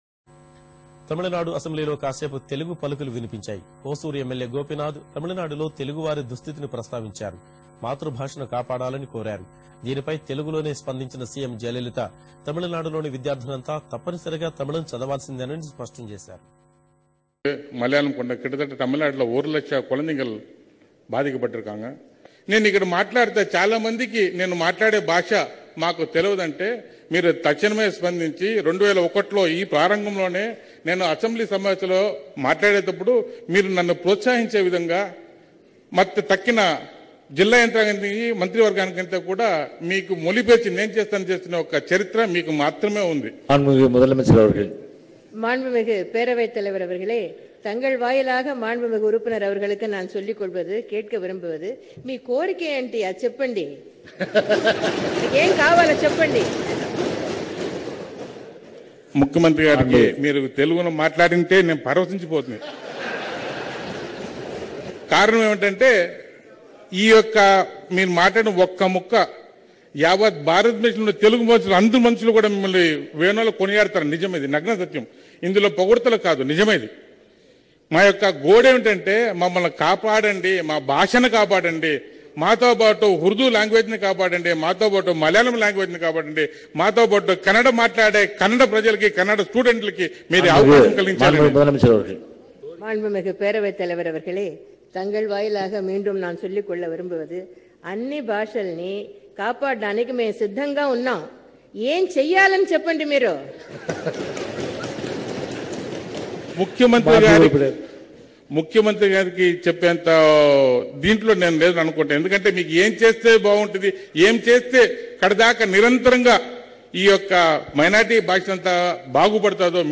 JAYALALITHA amma Superb TELUGU Speech in Taminadu assembly  Soul Of Bharat.wav